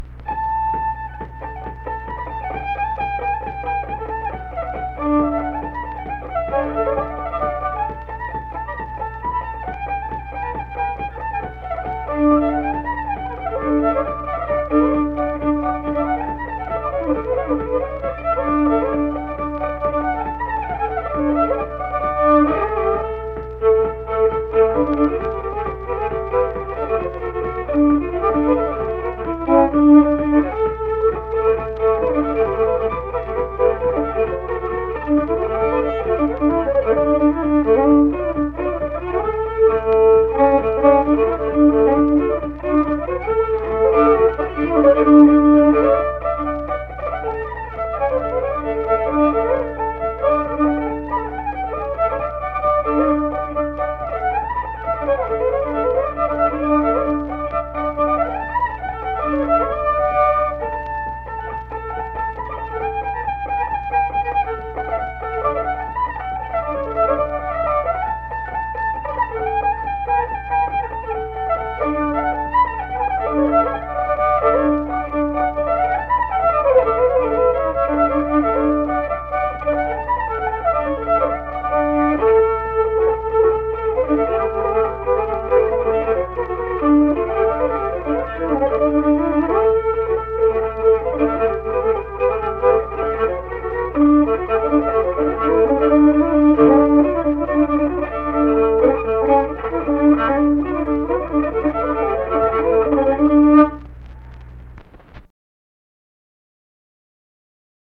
Unaccompanied fiddle music
Verse-refrain 2(2).
Instrumental Music
Fiddle